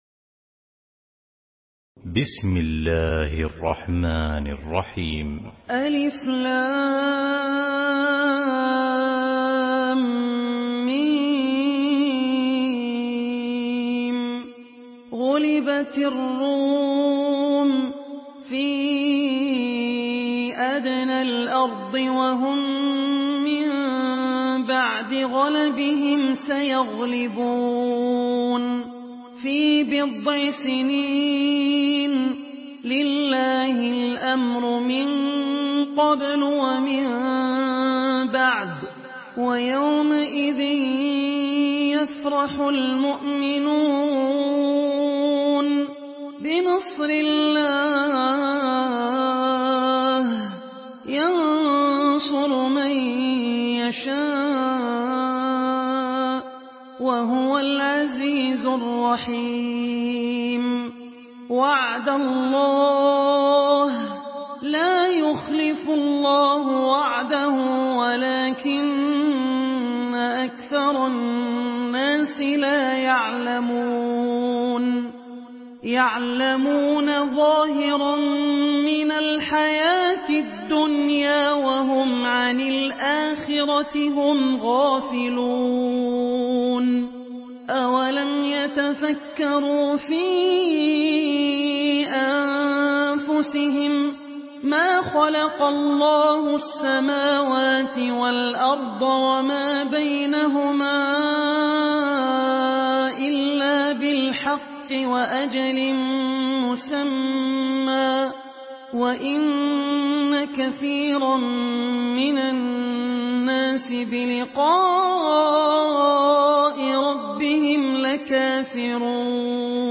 Surah আর-রূম MP3 by Abdul Rahman Al Ossi in Hafs An Asim narration.